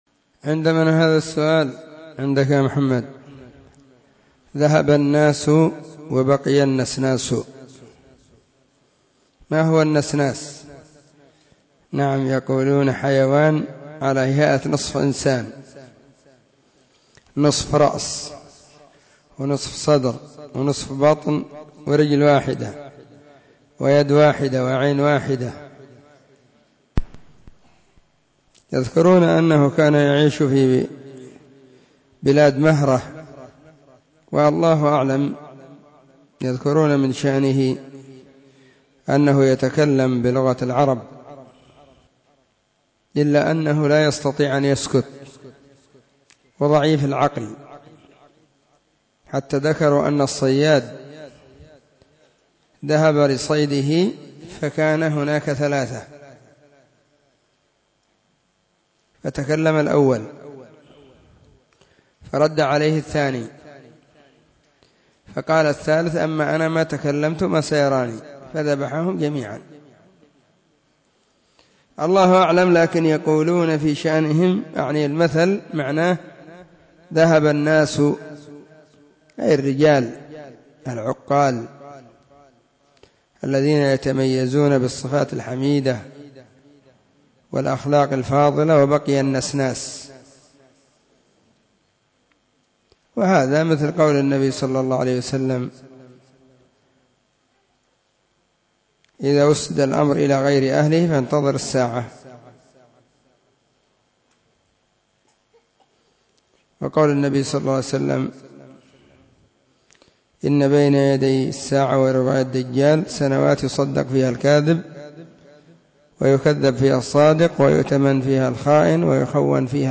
📢 مسجد الصحابة – بالغيضة – المهرة، اليمن حرسها الله.